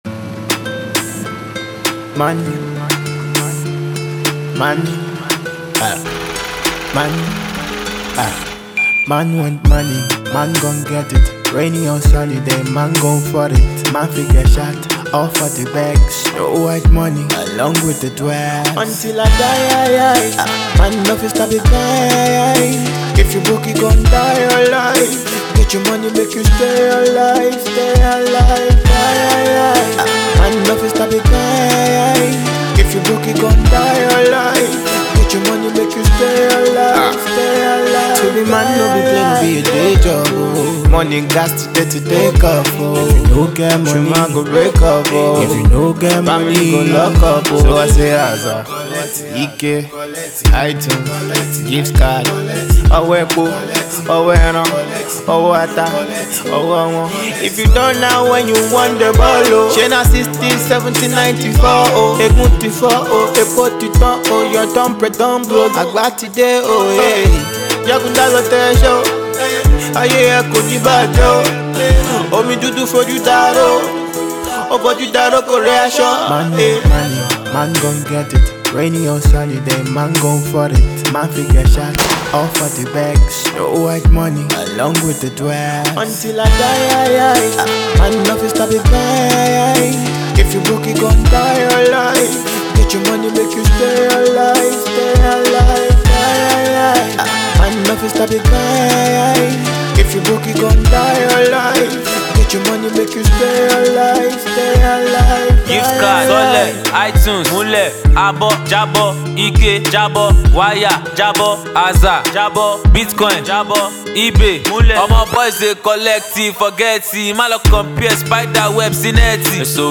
energetic performance